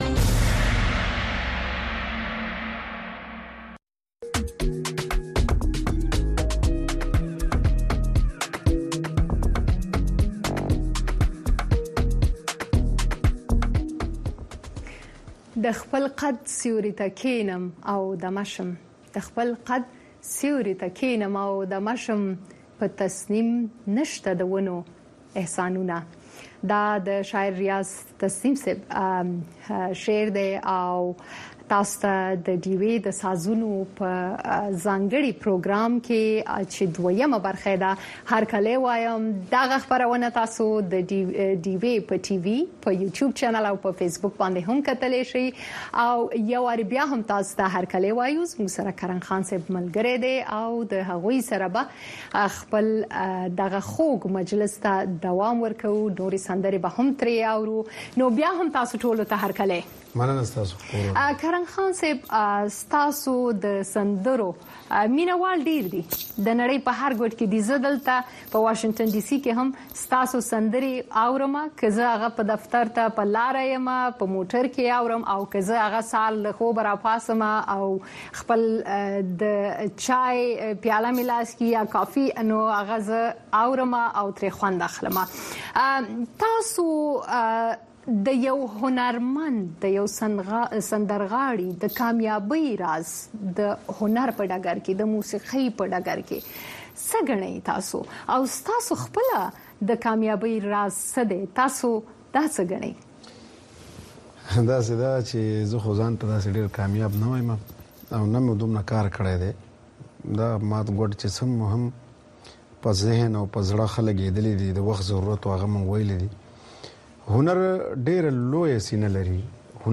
خبرونه
د وی او اې ډيوه راډيو سهرنې خبرونه چالان کړئ اؤ د ورځې دمهمو تازه خبرونو سرليکونه واورئ.